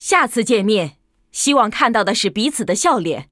文件 文件历史 文件用途 全域文件用途 Lobato_fw_03.ogg （Ogg Vorbis声音文件，长度3.3秒，103 kbps，文件大小：42 KB） 文件说明 源地址:游戏语音 文件历史 点击某个日期/时间查看对应时刻的文件。 日期/时间 缩略图 大小 用户 备注 当前 2018年11月17日 (六) 03:34 3.3秒 （42 KB） 地下城与勇士  （ 留言 | 贡献 ） 分类:洛巴赫 分类:地下城与勇士 源地址:游戏语音 您不可以覆盖此文件。